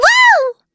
Taily-Vox-Laugh.wav